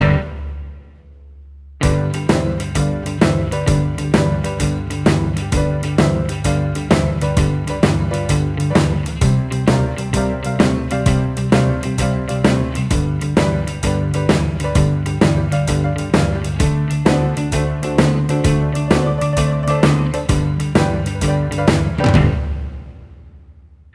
Minus Lead Guitar Rock 'n' Roll 2:45 Buy £1.50